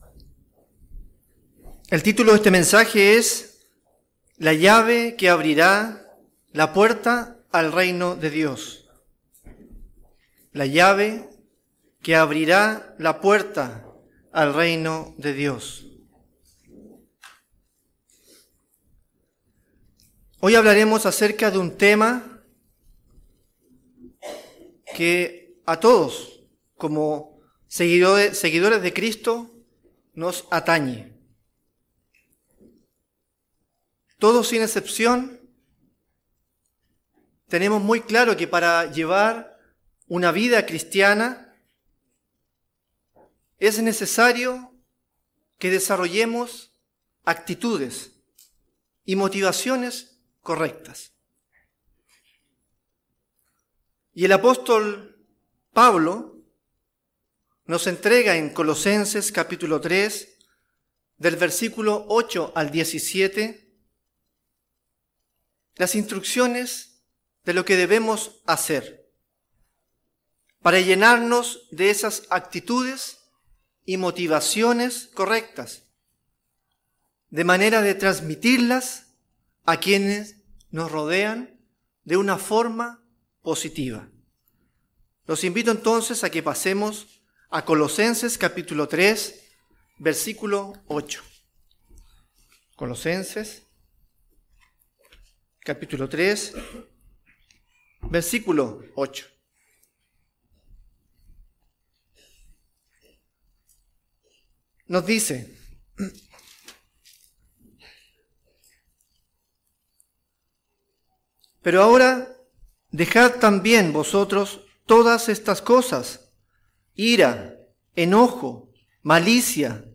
Given in Santiago